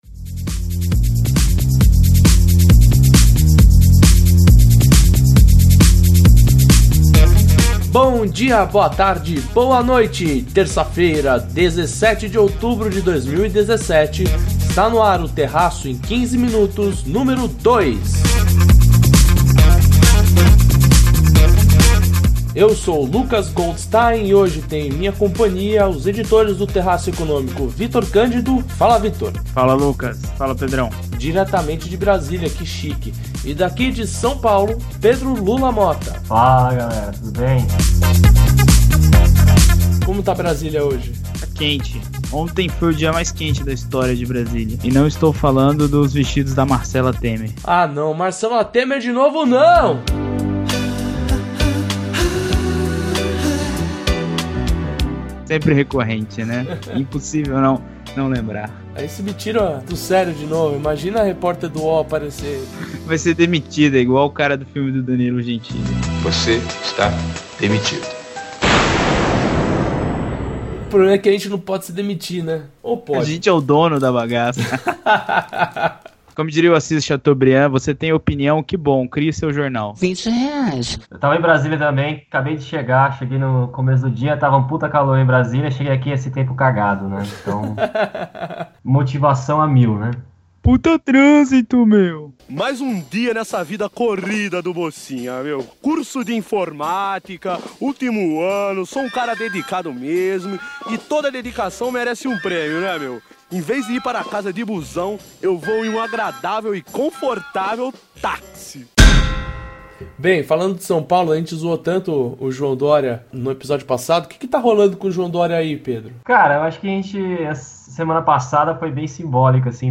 Sua mistura de análise, economia e bom humor para enfrentar a semana por vir.